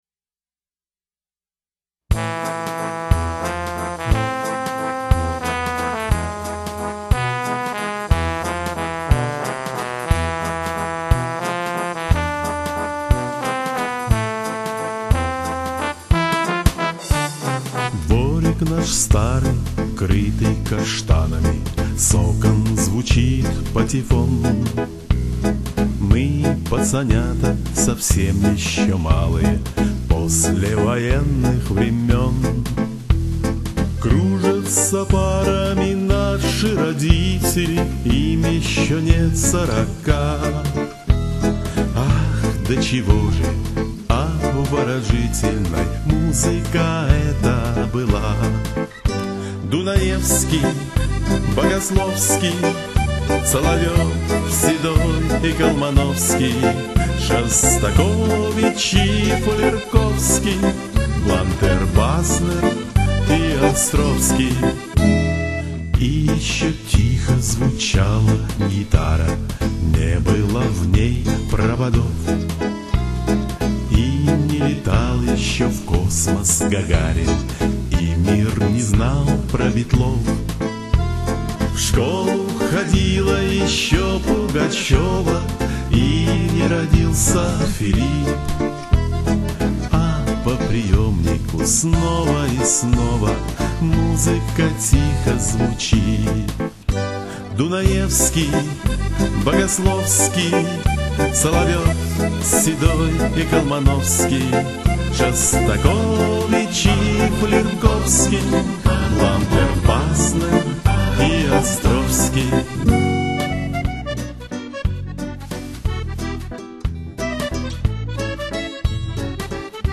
У нас в гостях  автор и исполнитель